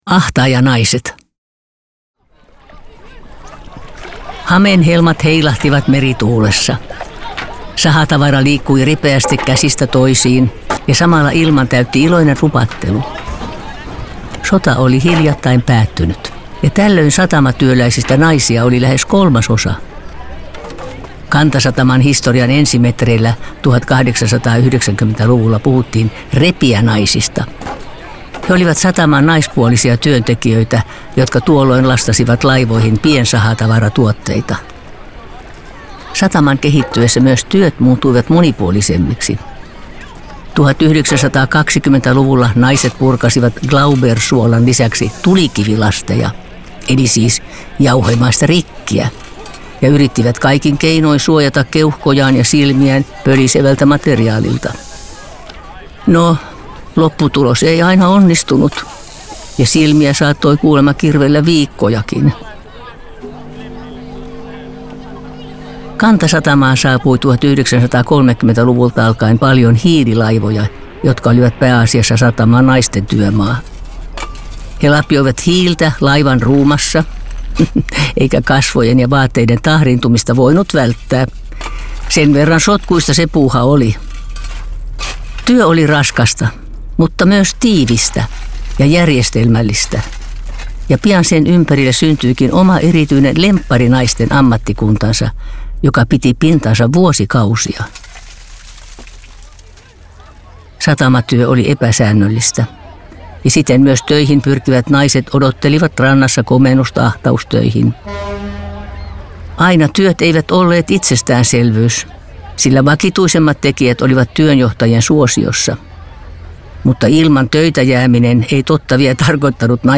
Syksyllä 2024 julkaistiin viisi uutta jaksoa, joiden lukijana toimi ikoninen Seela Sella (jaksot 8–12).